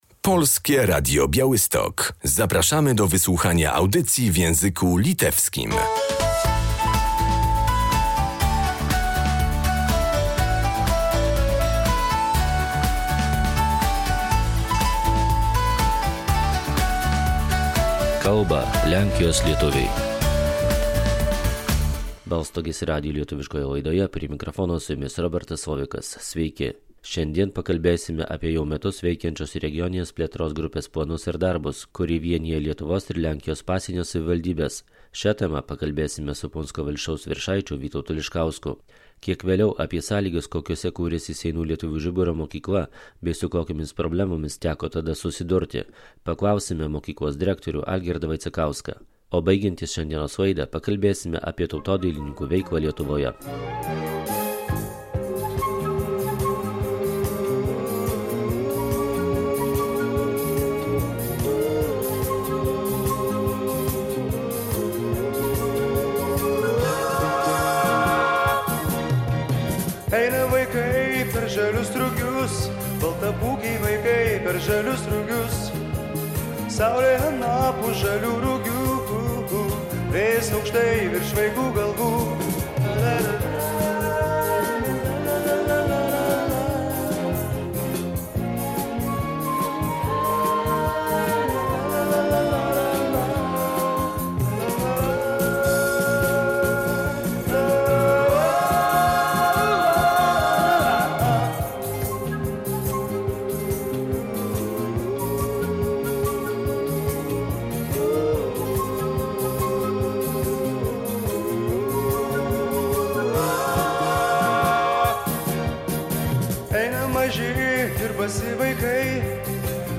Co może dać wspólna inicjatywa zrzeszająca samorządy z pogranicza polsko-litewskiego? O celach i możliwościach współpracy w ramach Europejskiego Ugrupowania Współpracy Transgranicznej zapytaliśmy dziś jej prezesa wójta gminy Puńsk Witolda Liszkowskiego.